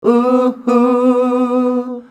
UUUHUUH.wav